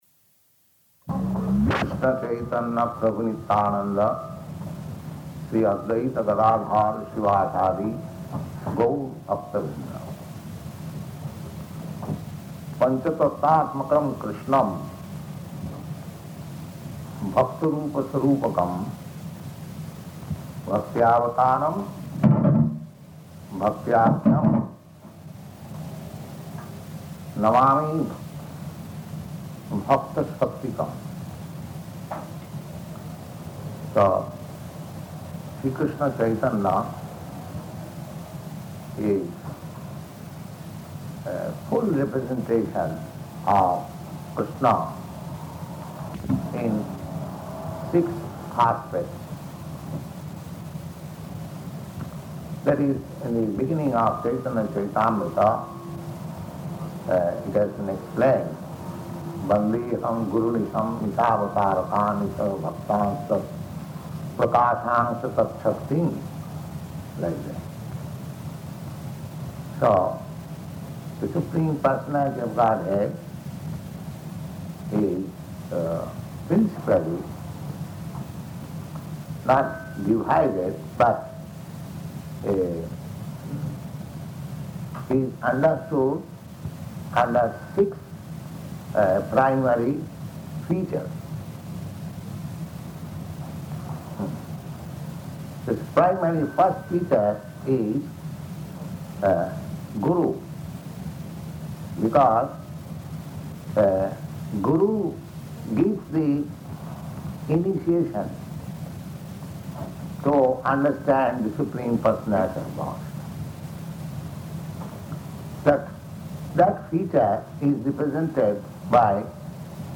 Lecture [partially recorded]
Location: Montreal